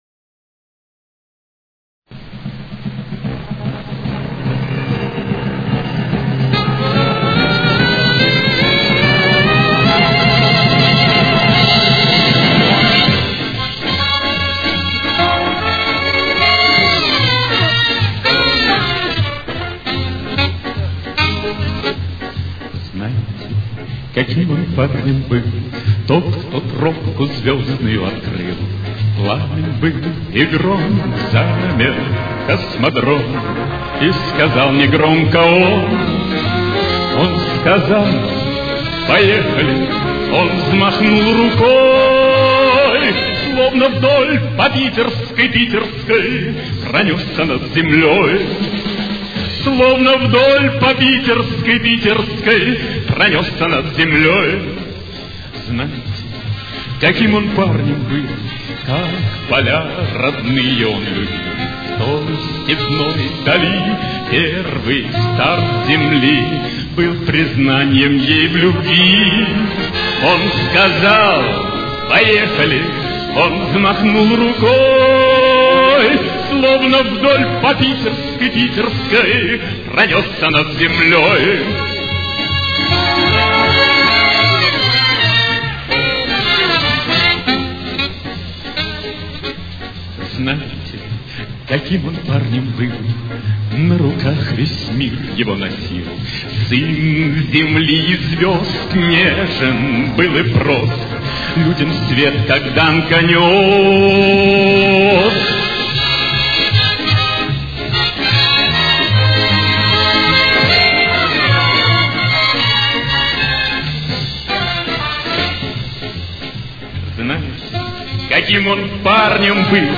Фа минор. Темп: 155.